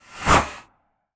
pgs/Assets/Audio/Guns_Weapons/Bullets/bullet_flyby_slow_02.wav at master
bullet_flyby_slow_02.wav